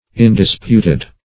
Indisputed \In`dis*put"ed\, a.
indisputed.mp3